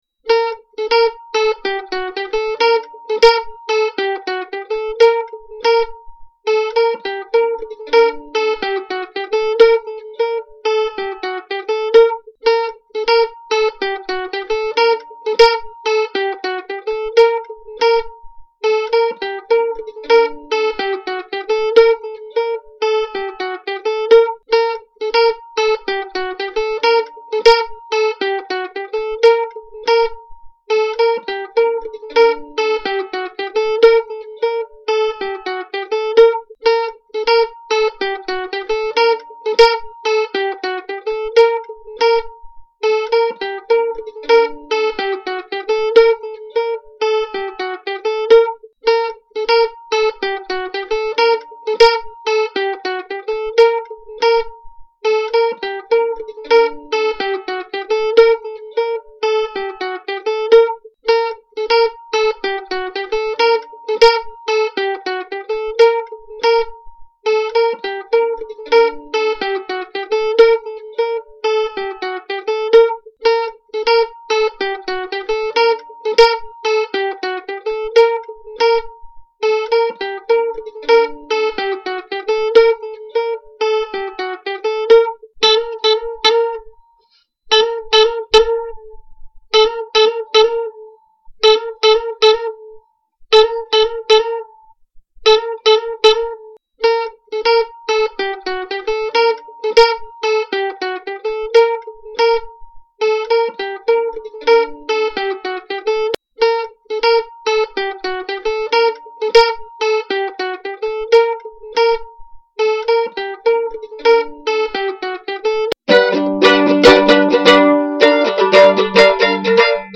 Tags: mandolin jam music